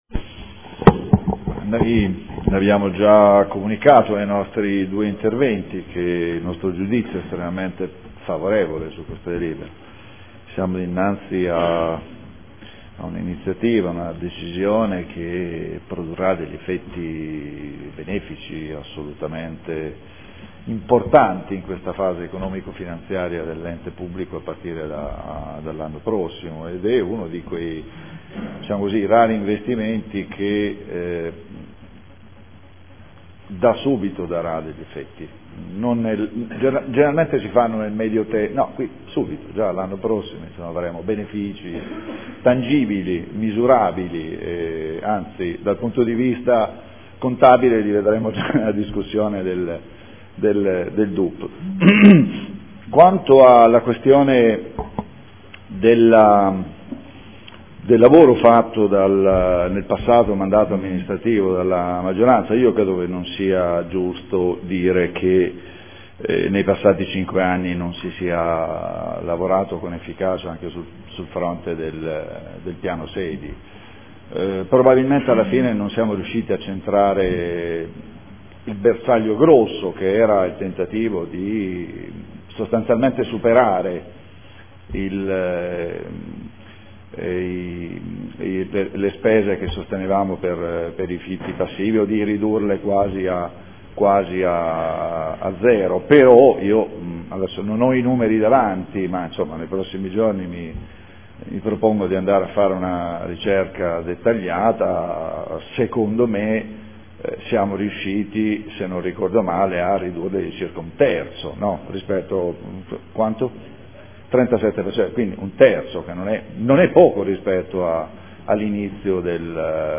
Paolo Trande — Sito Audio Consiglio Comunale
Dichiarazione di voto